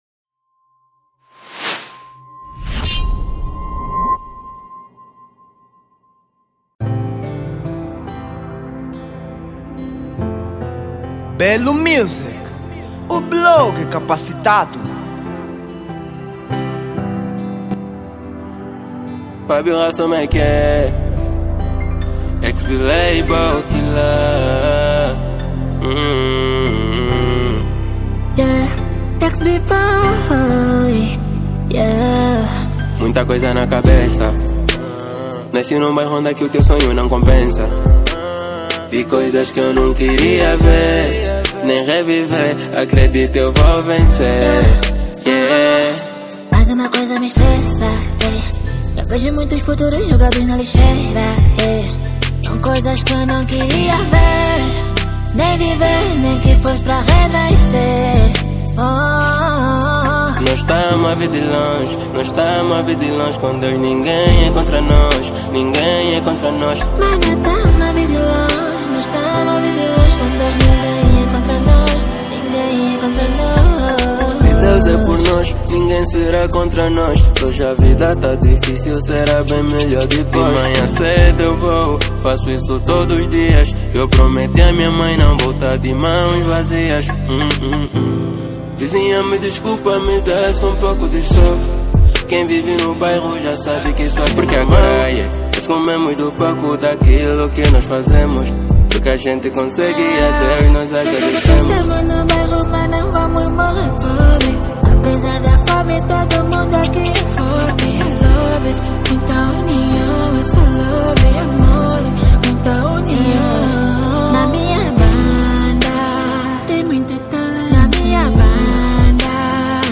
Género: Trap